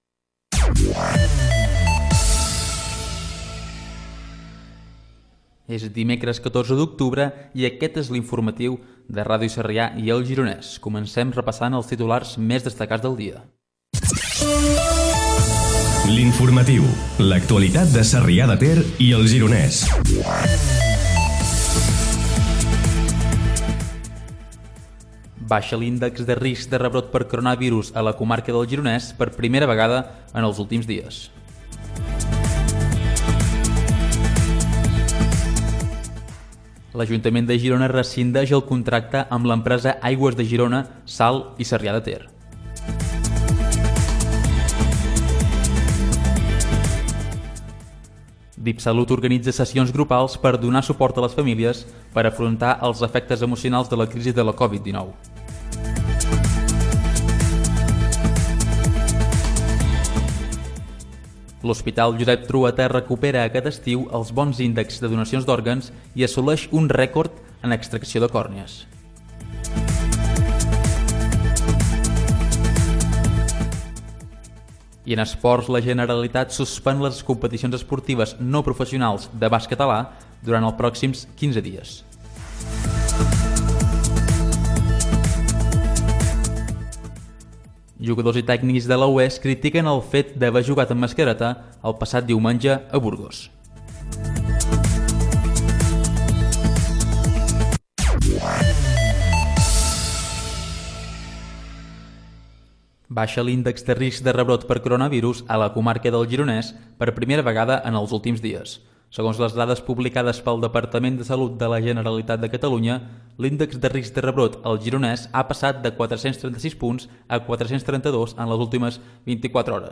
Data, identificació, careta del programa, sumari informatiu, baixa l'índex de rebrot de Coronavirus.
Informatiu